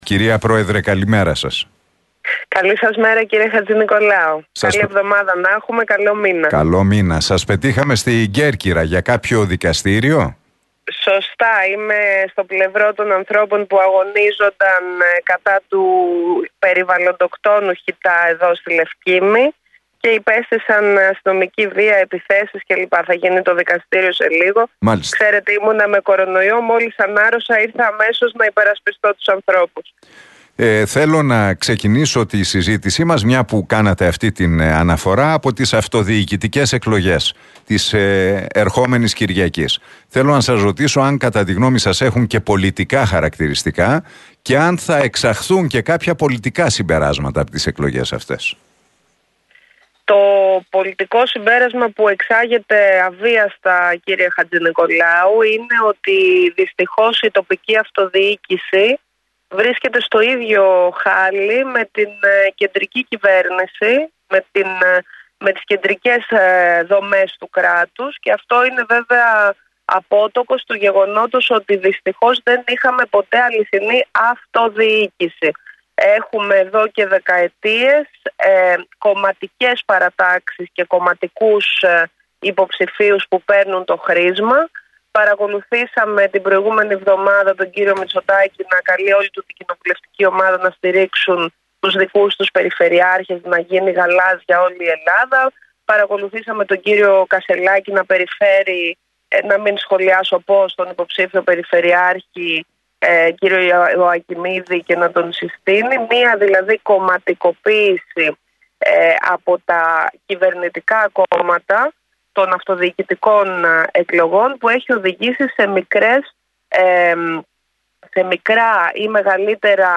Στην εκπομπή του Νίκου Χατζηνικολάου στον Realfm 97,8 παραχώρησε συνέντευξη η πρόεδρος της Πλεύσης Ελευθερίας, Ζωή Κωνσταντοπούλου.